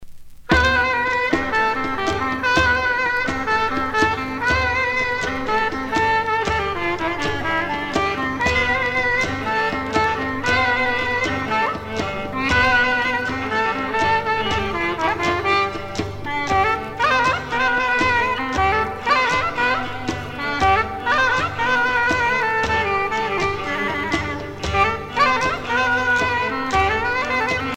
danse : syrtos (Grèce)
Pièce musicale éditée